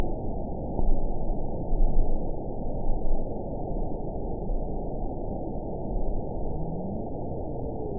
event 922133 date 12/27/24 time 00:42:29 GMT (5 months, 3 weeks ago) score 9.36 location TSS-AB10 detected by nrw target species NRW annotations +NRW Spectrogram: Frequency (kHz) vs. Time (s) audio not available .wav